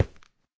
stone5.ogg